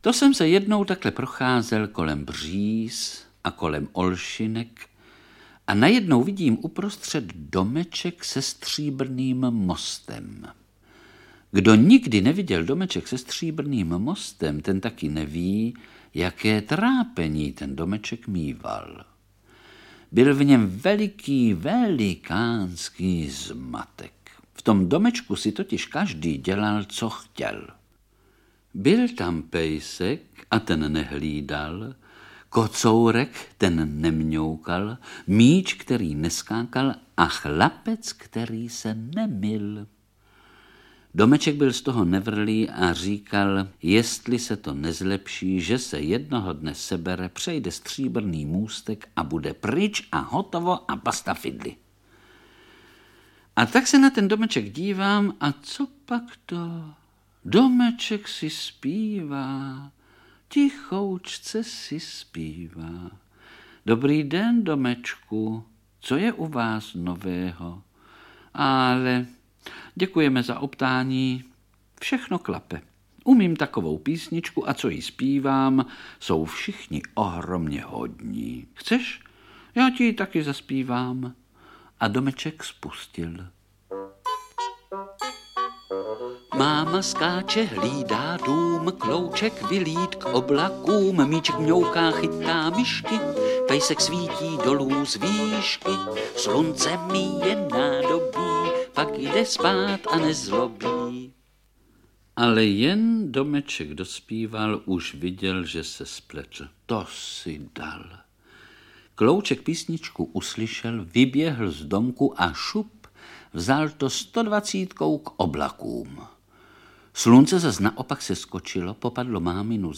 Ukázka z knihy
Třináctý díl miniserie Album pohádek "Supraphon dětem" obsahuje pouze pohádky vyprávěné.
• InterpretEduard Cupák, Vlastimil Brodský, František Filipovský, Jaroslav Kepka, Karel Höger, Bohumil Švarc